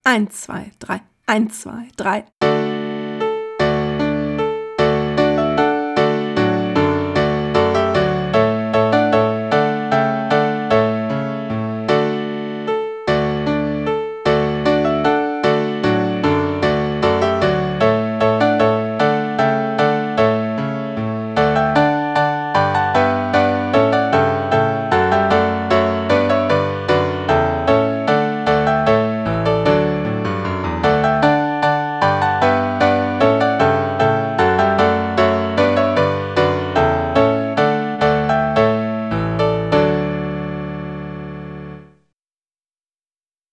So ist das neueste Projekt eine Mehrspuraufnahme  von fünf Sätzen aus der Feuerwerksmusik von Händel, wo jede/r Schüler*in zu einem vorgefertigten Playback einzeln seine/ihre Stimme einspielt.
Für die ersten, die sich mutig getraut haben, bestand das Playback aus einer Midi-Klavieraufnahme (